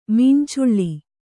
♪ mīncuḷḷi